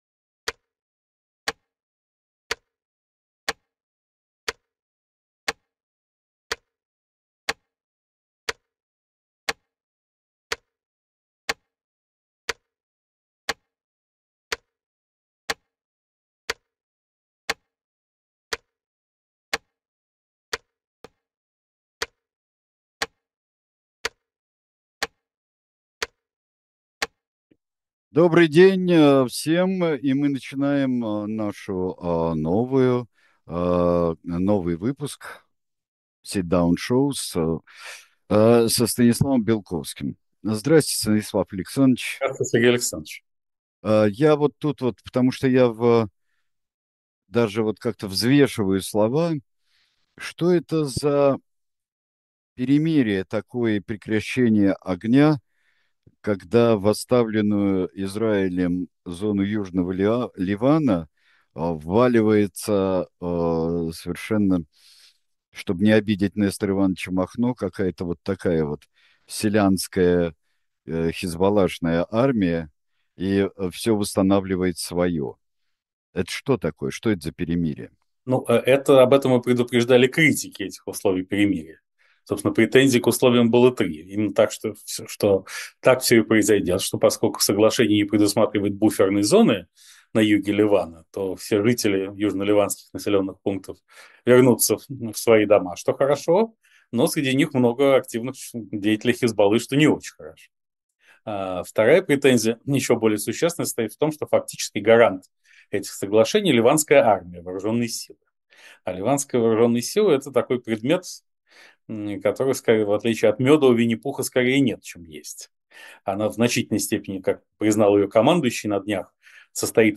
Эфир ведёт Сергей Бунтман